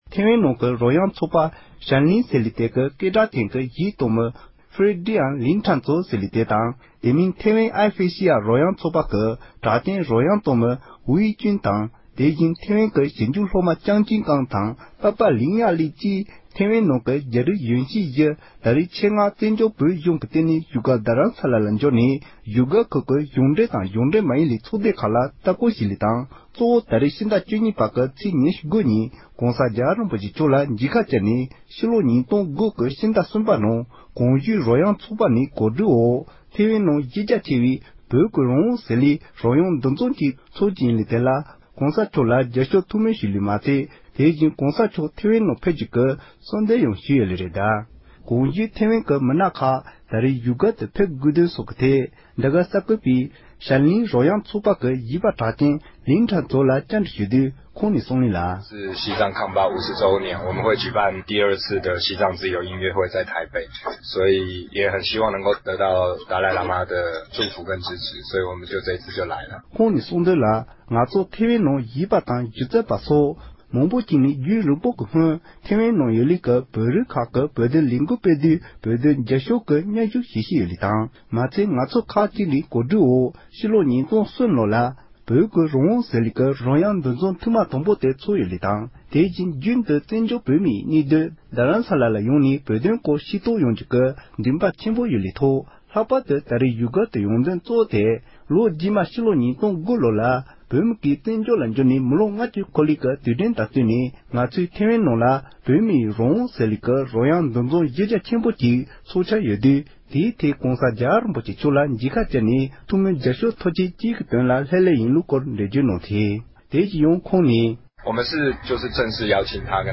ཐེ་ལྦན་གཞས་པ་གྲགས་ཅན་རྡ་སར་ཕེབས། ཐེ་ལྦན་གཞས་པ་གྲགས་ཅན་ཕརེ་ཌི་ཡིས་གསལ་འགོད་པའི་ལྷན་བཀའ་མོལ་གནང་བཞིན་པ།
སྒྲ་ལྡན་གསར་འགྱུར། སྒྲ་ཕབ་ལེན།